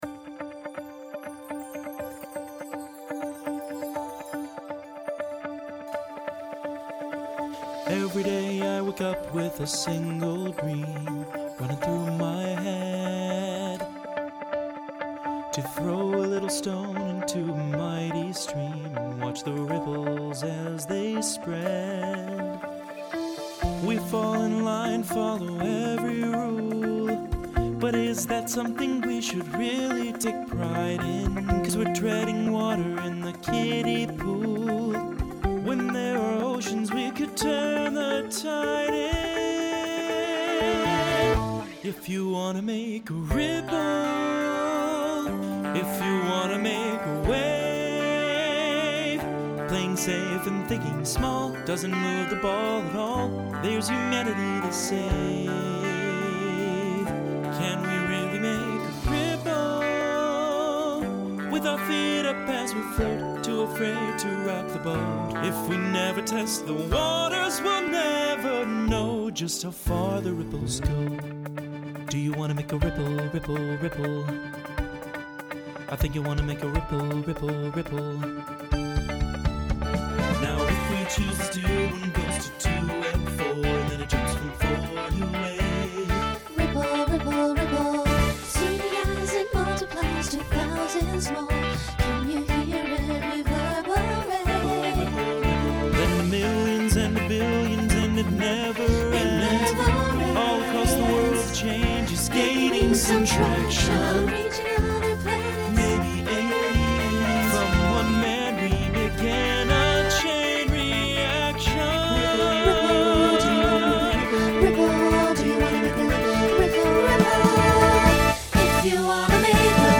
Genre Broadway/Film , Holiday Instrumental combo
Transition Voicing SATB